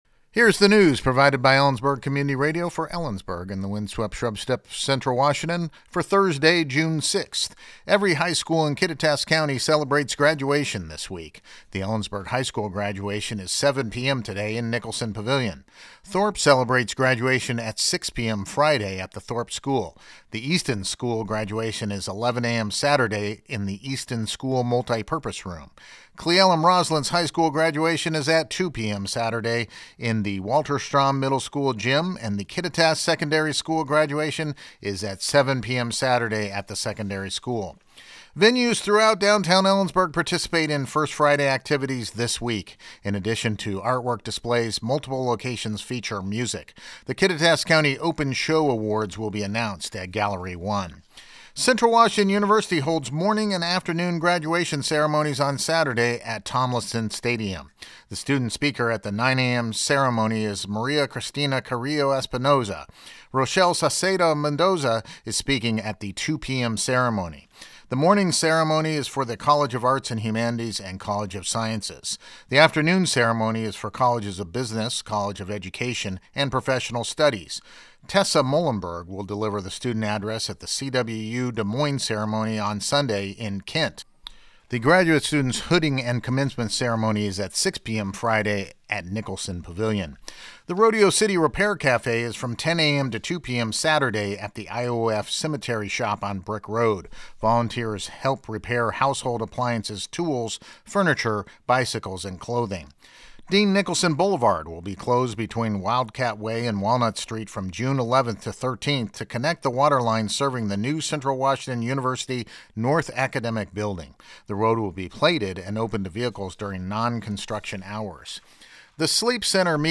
LISTEN TO THE NEWS HERE NEWS High School graduation this week Every high school in Kittitas County celebrates graduation this week.